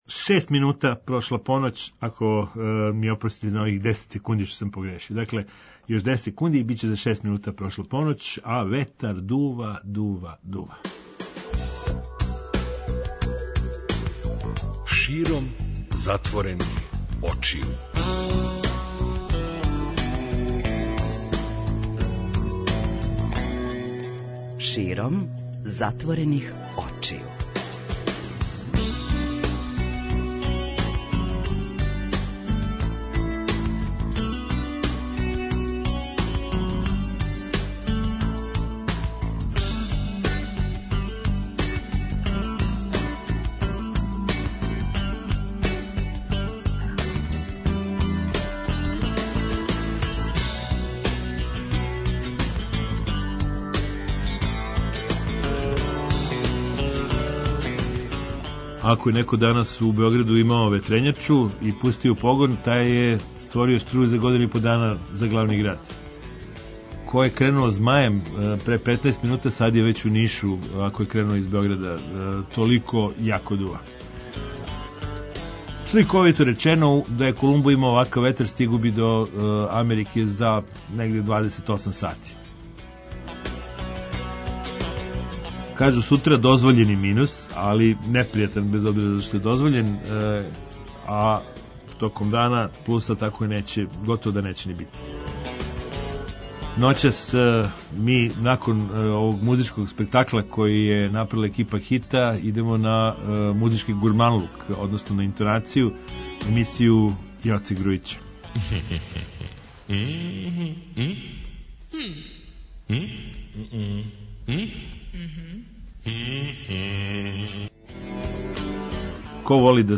преузми : 54.85 MB Широм затворених очију Autor: Београд 202 Ноћни програм Београда 202 [ детаљније ] Све епизоде серијала Београд 202 Тешке боје Пролеће, КОИКОИ и Хангар Устанак Устанак Устанак